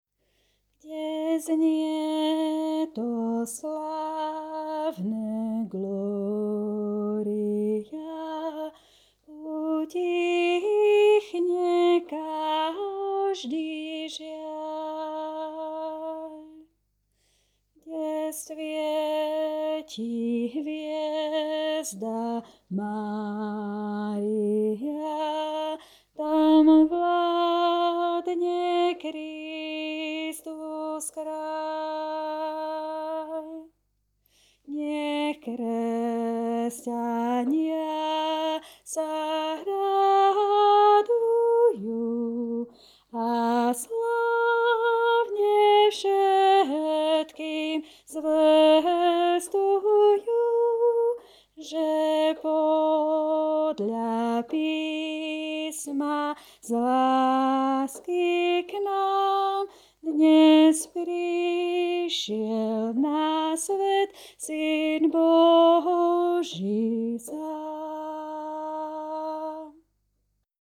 Znie_nad_Betlehemom_Gloria-Bass.mp3